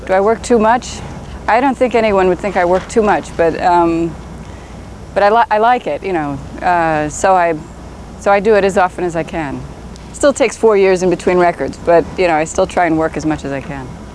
Suzanne Vega's Paris Video Interview